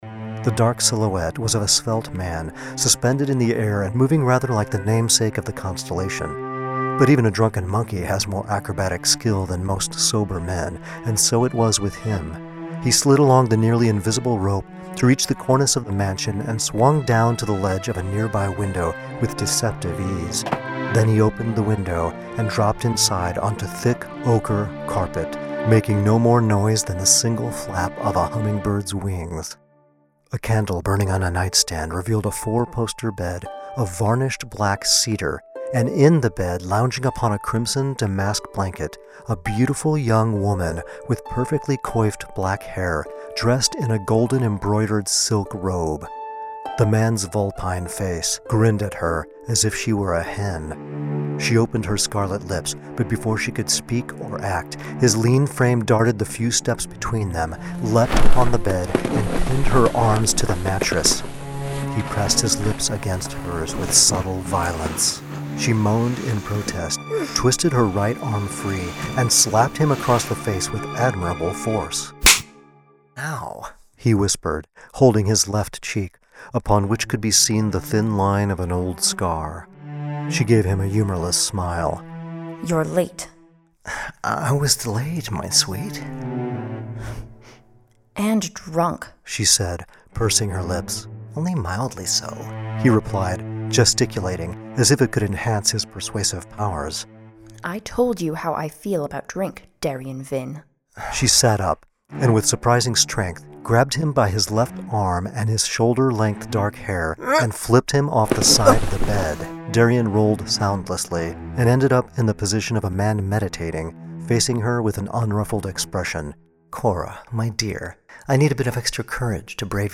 Dream Tower Media creates full audiobook dramas featuring professional voice actors, sound effects, and full symphonic musical scores.
Excerpts from One Night in Merth audiobook drama